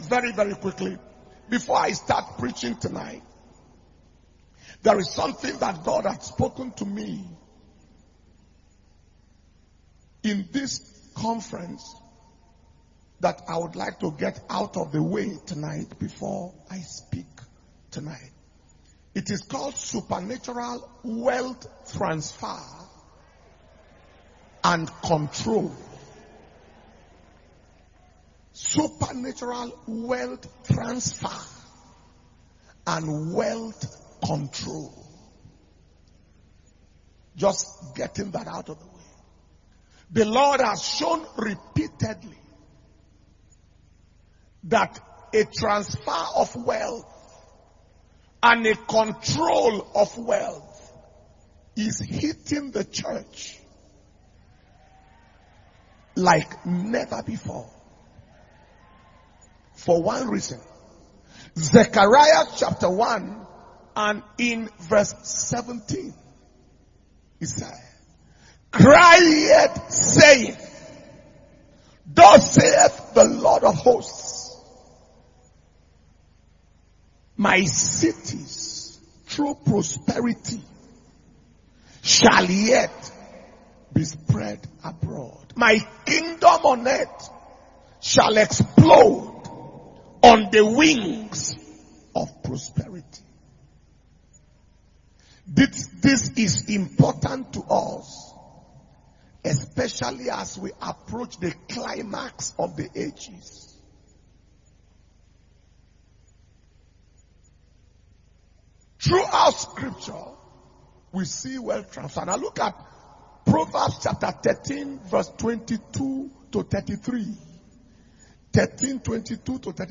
Kingdom Power And Glory World Conference – Day 5 – Worship, Word And Wonders Night – Friday, 26th November 2021